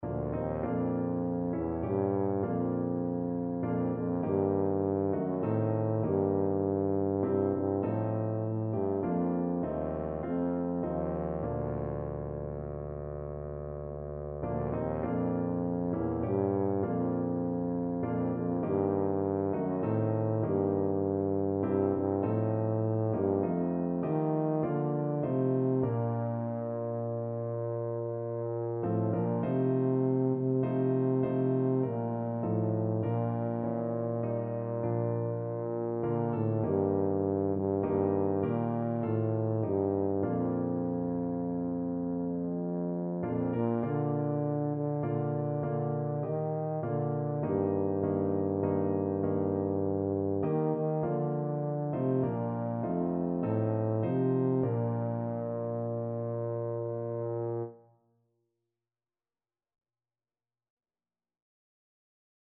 Tuba version
Christian